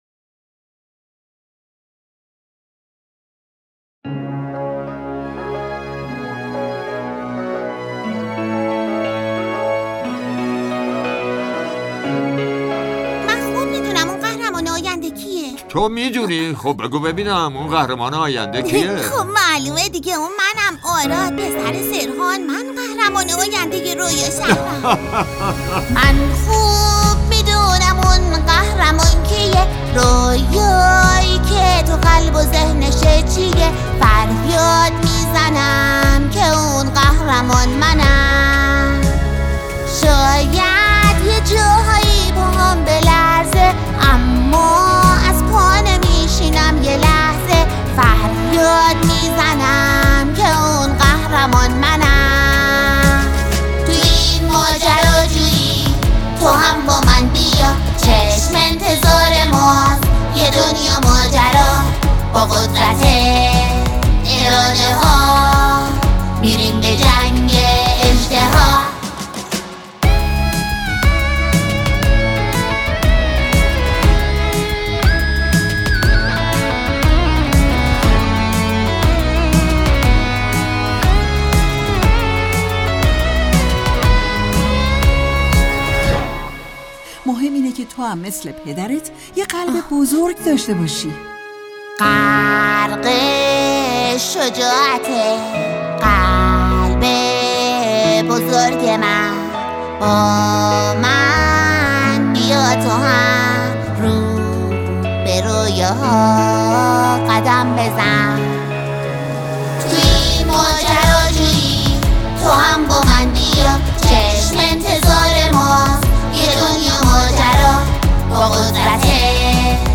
قطعه سرود